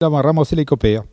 Patois - archives
Catégorie Locution